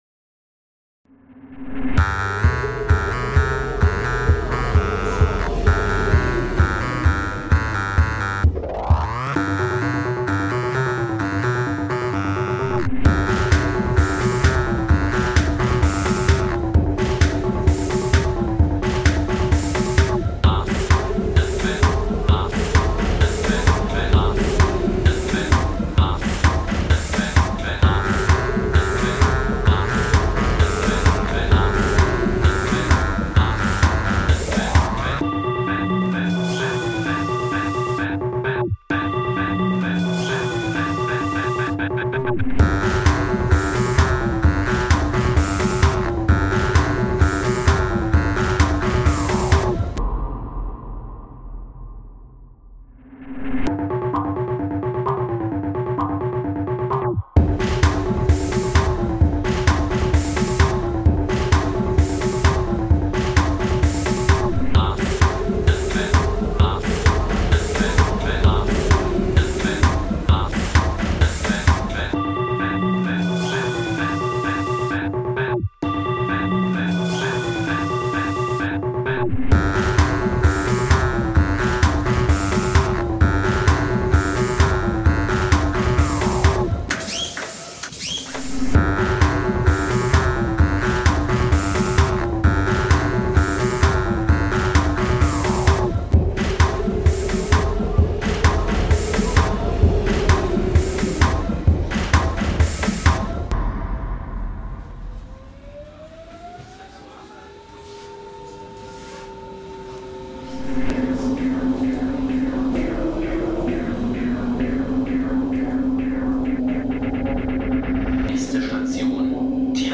The overall piece sounded like a retro computer game.